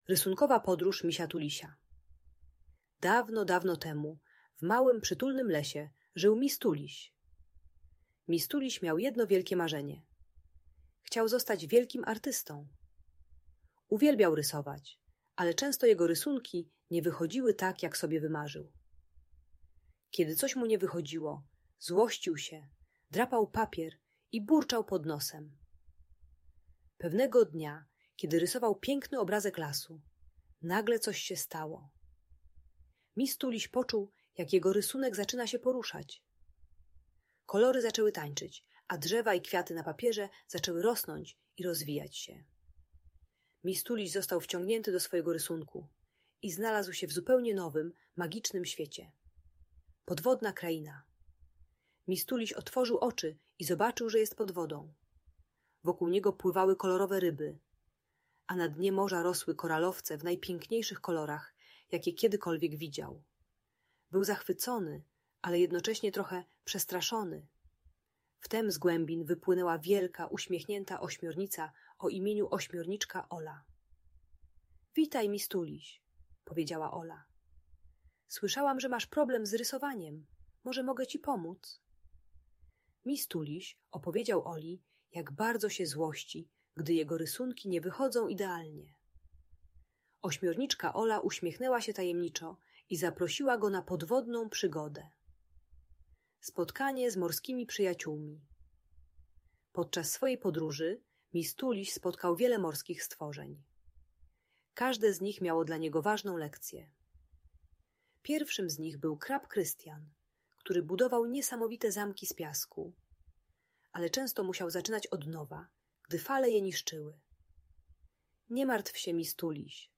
Rysunkowa podróż Misia Tulisia - magiczna historia - Audiobajka